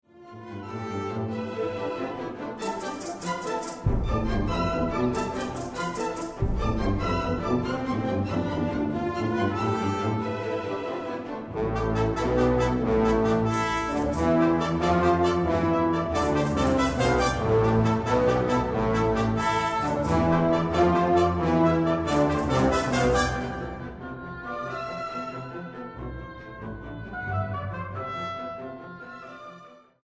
Grabado en: Teatro Aguascalientes, marzo, 2013.